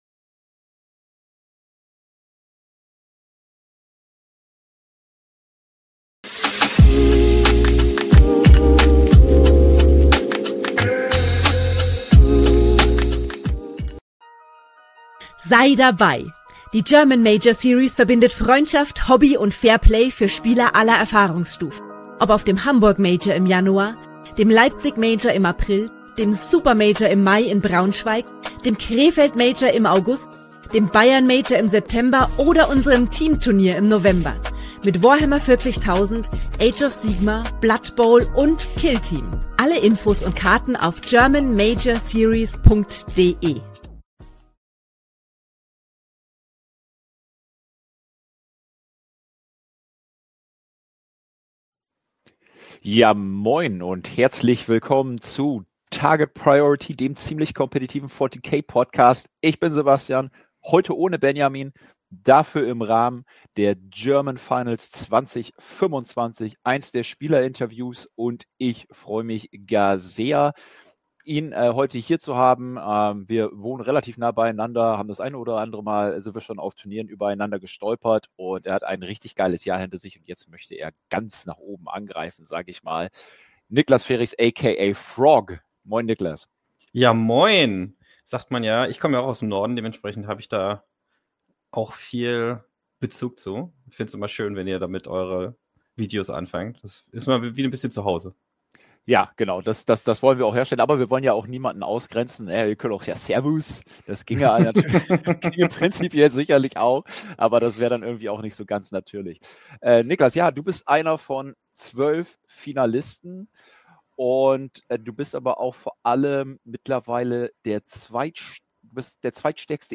Podcast_German_Finals_2025_Interview.mp3